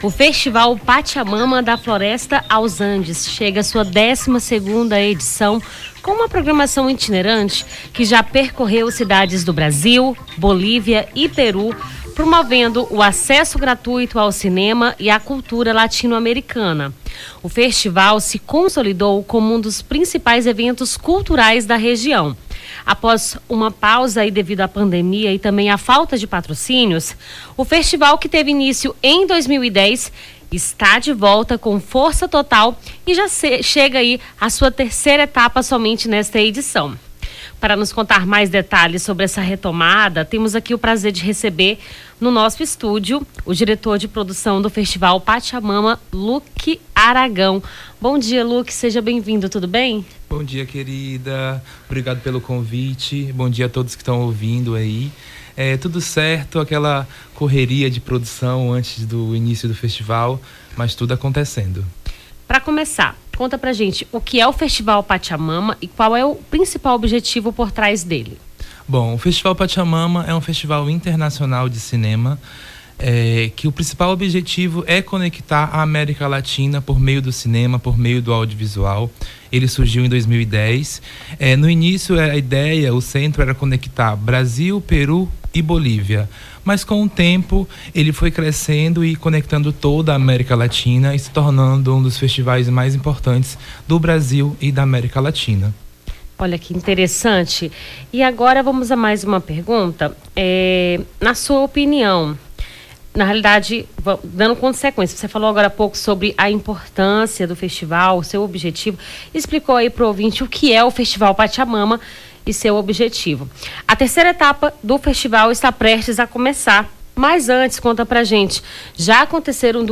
Nome do Artista - CENSURA - ENTREVISTA FESTIVAL PACHAMAMA (06-12-24).mp3